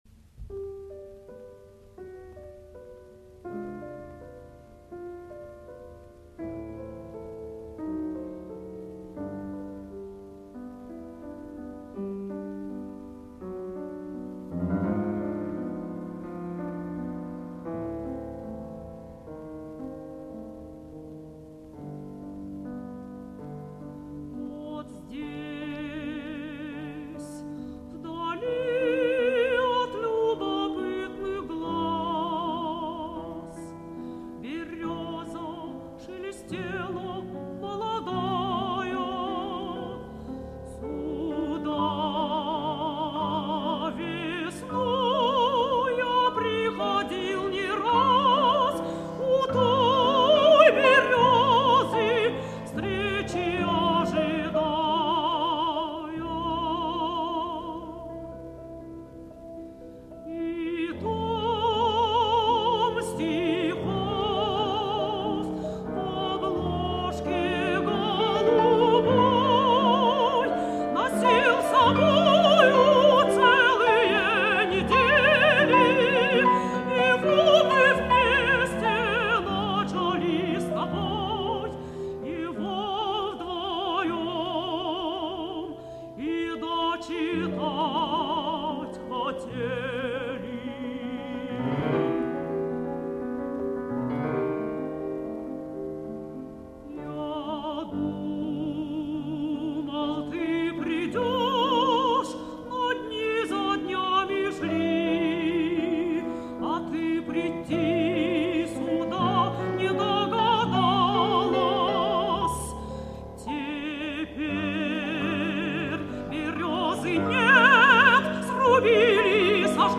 Режим: Stereo